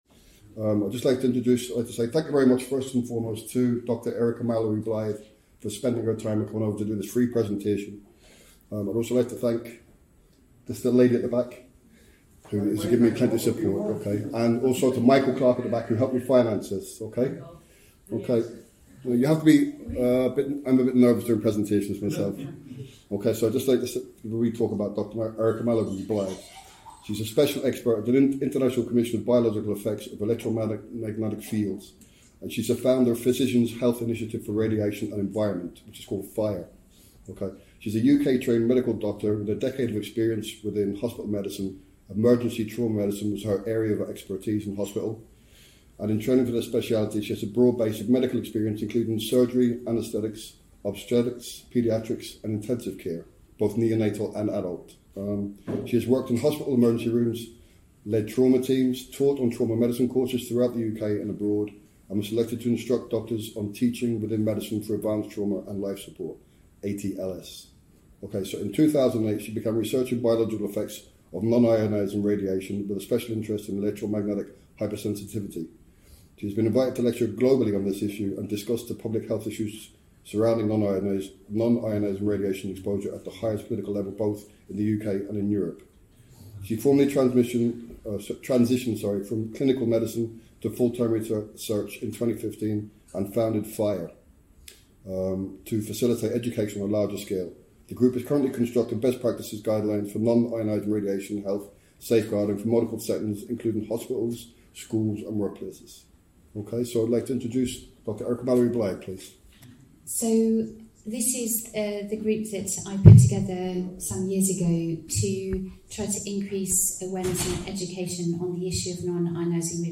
Presentation about the dangers of WiFi & Microwave Radiation in our schools CS Lewis suite, Ramada Hotel Belfast September 14th 2024Show more I approached every political party in Northern Ireland and asked them to attend or send a representative.